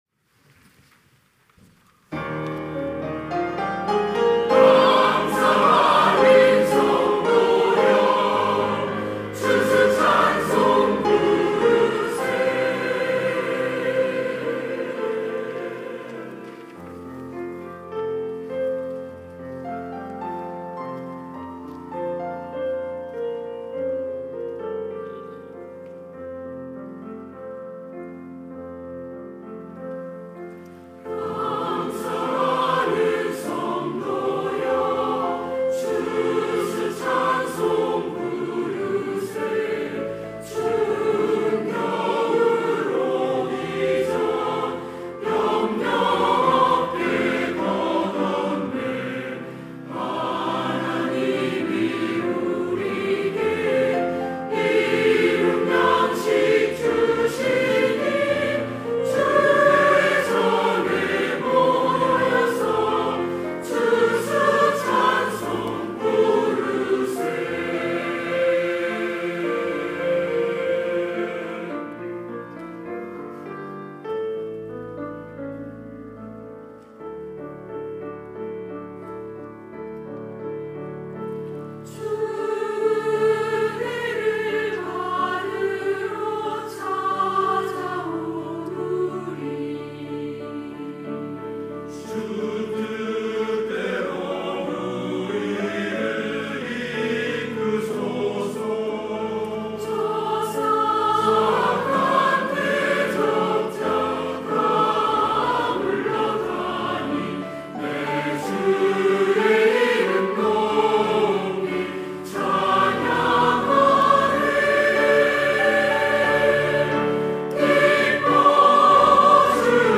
시온(주일1부) - 감사의 찬송
찬양대